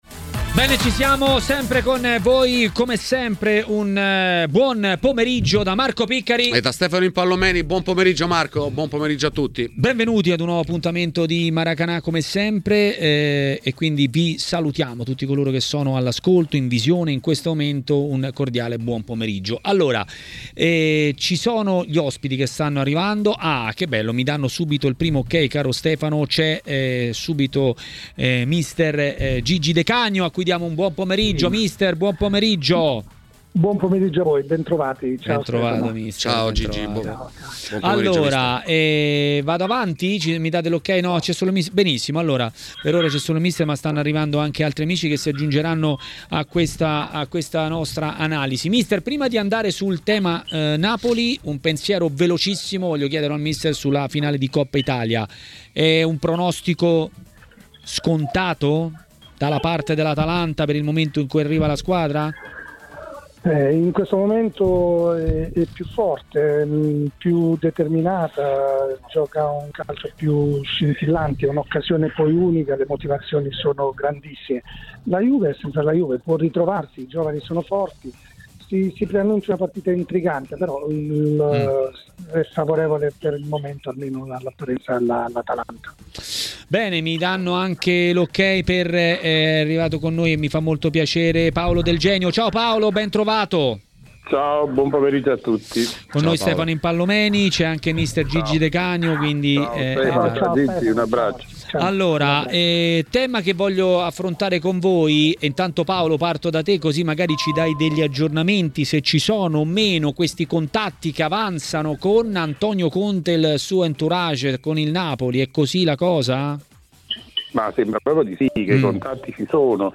Mister Luigi De Canio è stato ospite di TMW Radio, durante Maracanà.